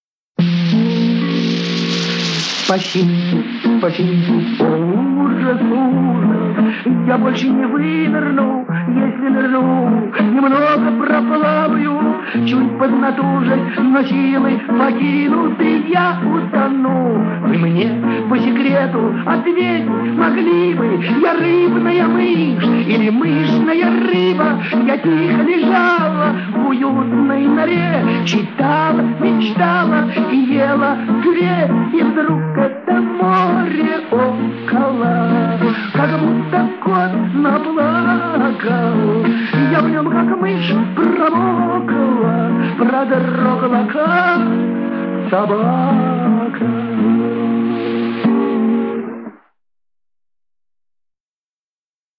музыка, слова и исполнение